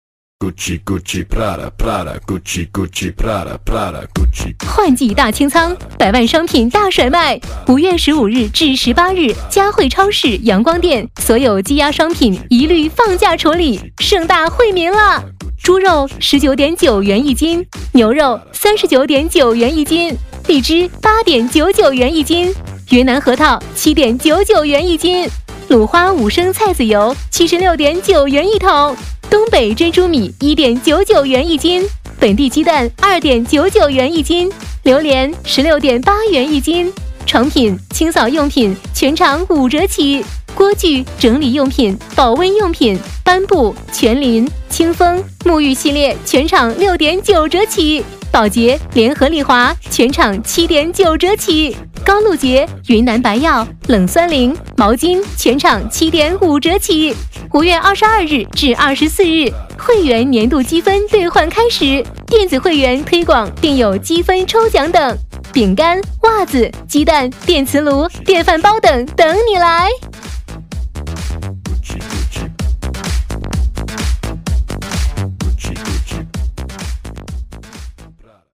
女22号配音师
全能配音员，声音知性、温柔、甜美、风格多变，专题、广告、旁白、仿童等可轻松驾驭 代表作品：旺旺系列广告、西湖博物馆、杭州博物馆、知名彩妆系列广告等 老师宣言：从业13年，声音传遍祖国各地，客户就是我最好的宣传。
代表作品 Nice voices 促销 彩铃 抖音 飞碟说 广告 旁白 童音 游戏 专题片 促销-女22-激情促销-端午节促销.mp3 复制链接 下载 促销-女22-激情促销-蚝情烤吧.mp3 复制链接 下载 促销-女22-温情促销-桃园三章.mp3 复制链接 下载 促销-女22-激情促销-超市促销.mp3 复制链接 下载